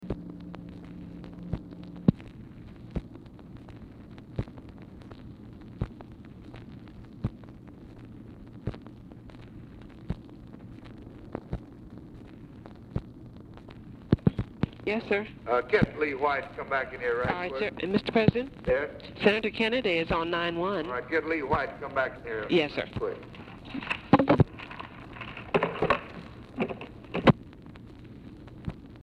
Telephone conversation # 3172, sound recording, LBJ and OFFICE SECRETARY, 4/28/1964, time unknown | Discover LBJ